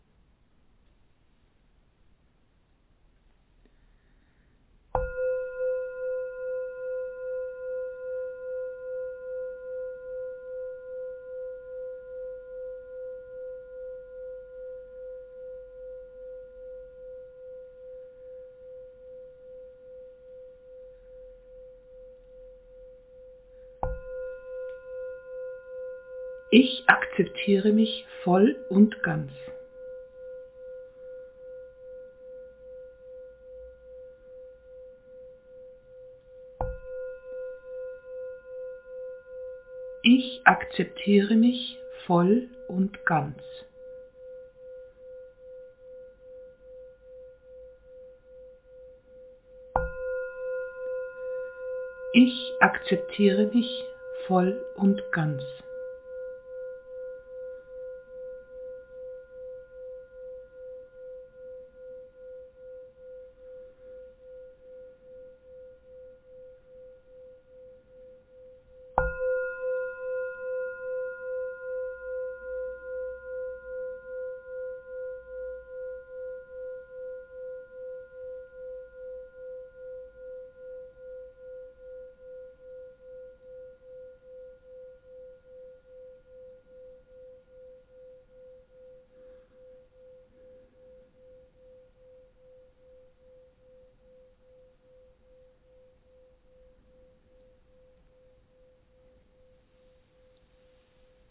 Tages-Affirmation